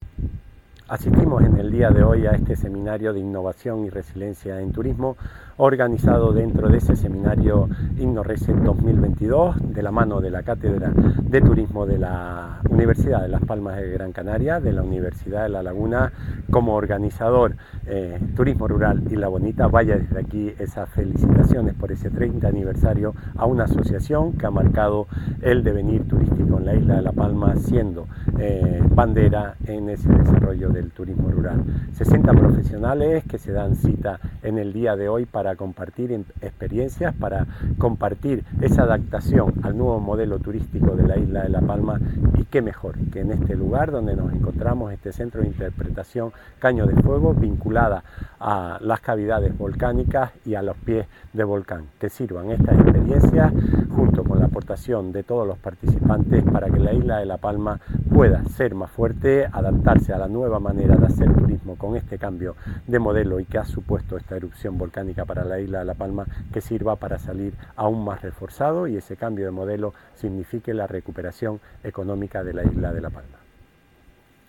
Declaraciones audio Raúl Camacho_0.mp3